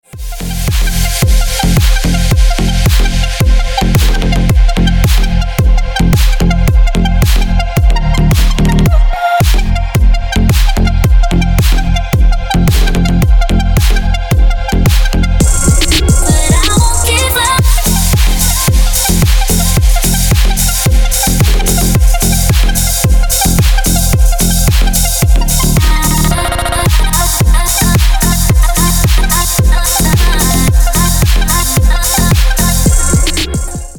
Клубные рингтоны
Клубная музыка на вызов